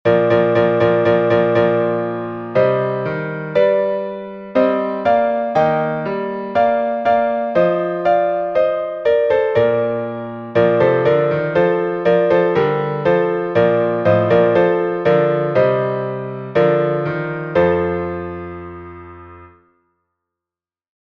Глас 1